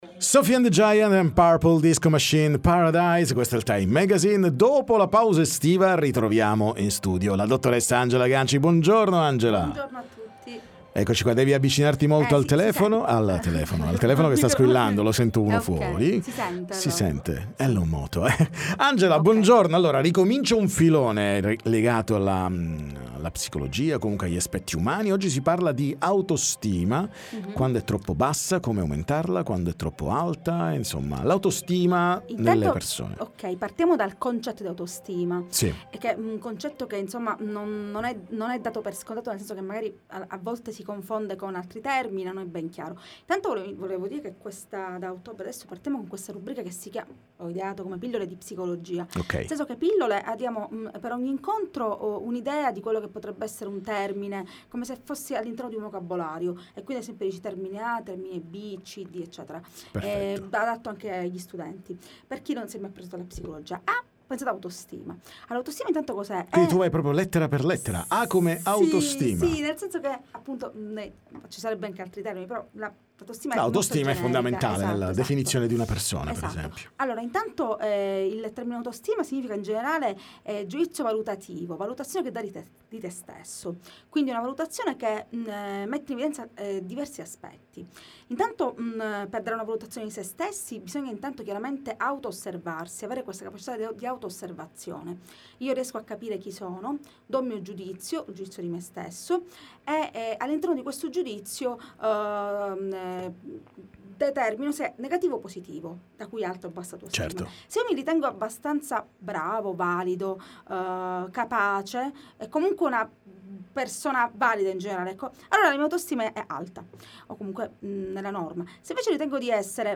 ai microfoni dei nostri studi